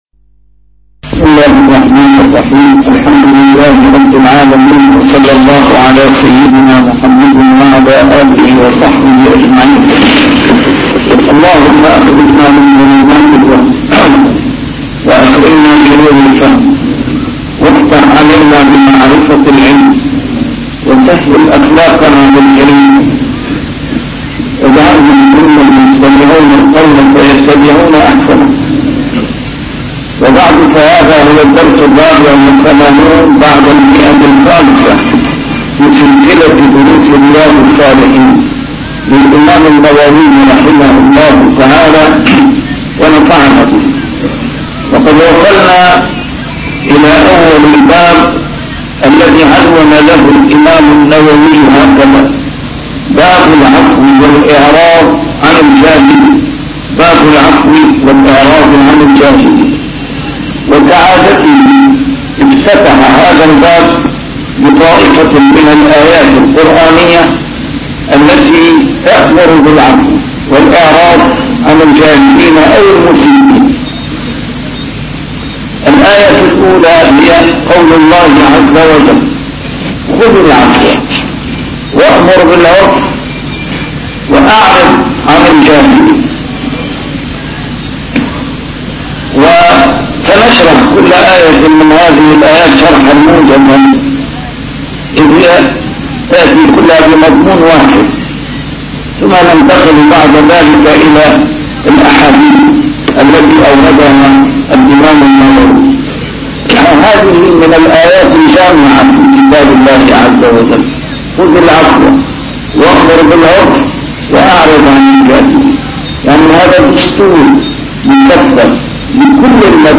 A MARTYR SCHOLAR: IMAM MUHAMMAD SAEED RAMADAN AL-BOUTI - الدروس العلمية - شرح كتاب رياض الصالحين - 584- شرح رياض الصالحين: العفو